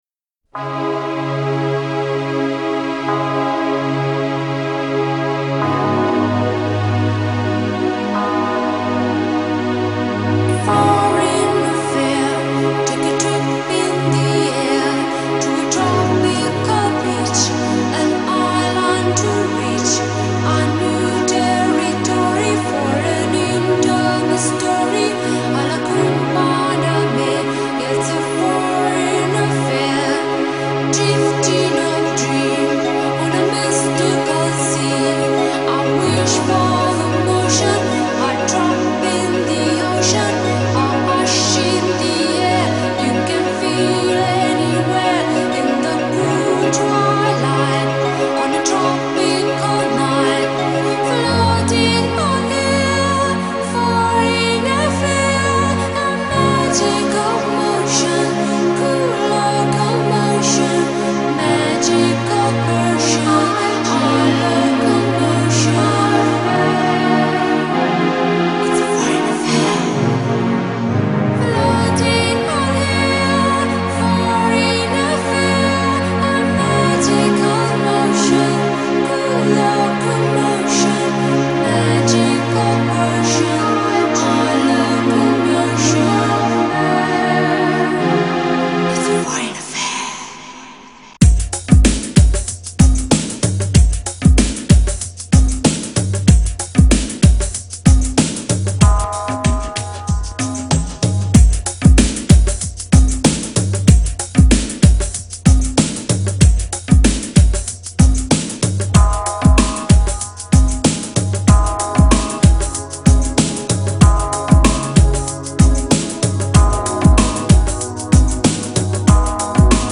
Аудиодорожка с клипа, немного отредактировал звук.